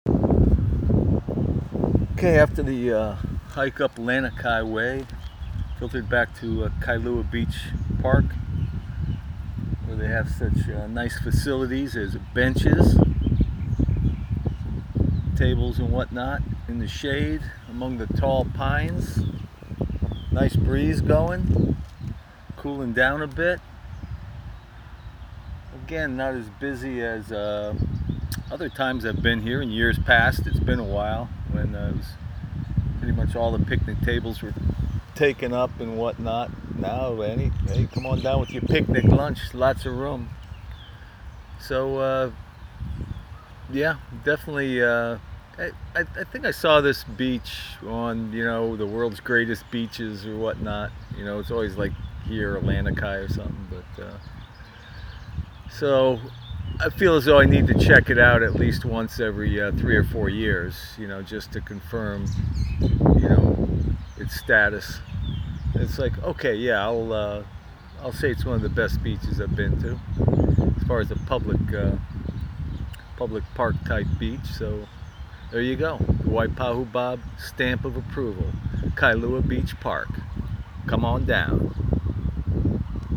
Back at Kailua Beach Park.
kailua-beach-park.mp3